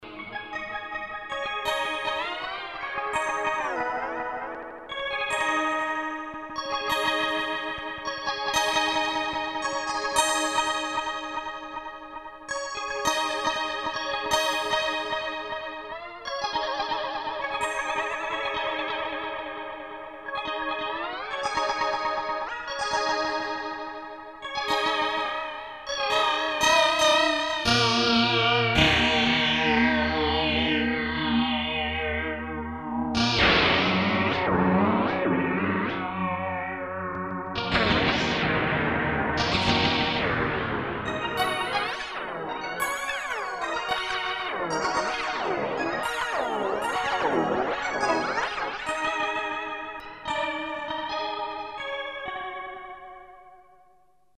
German rackmount hybrid analog-digital effect unit from early eighties generates reverb and delay.
Hear time tweaking